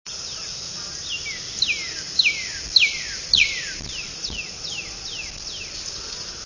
Northern Cardinal
Collegeville, PA, 3/15/03, pair of Cardinals duetting, (26kb)
cardinal_duet_693_mp3.wav